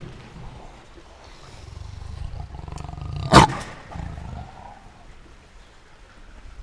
Directory Listing of //allathangok/nyiregyhazizoo2010_standardt/leopard_feketeparduc/
feketeparduc00.06.wav